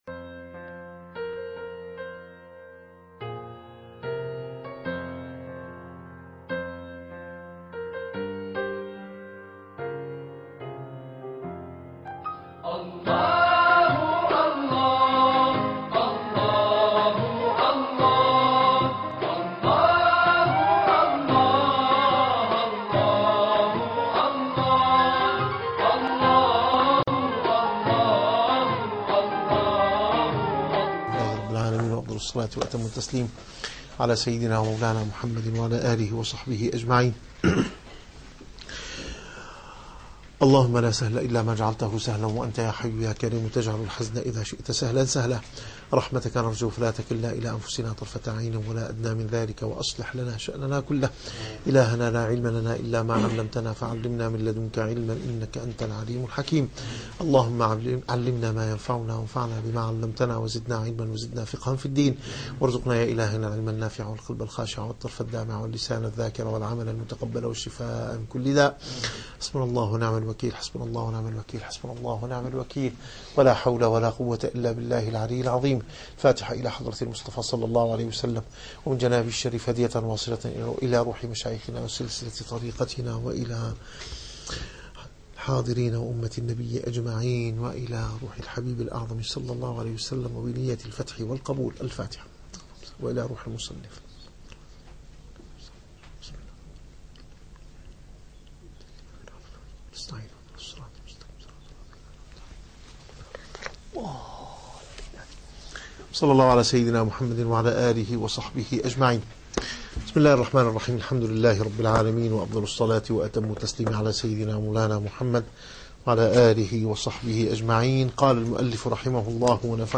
- الدروس العلمية - الرسالة القشيرية - الرسالة القشيرية / الدرس الرابع والأربعون.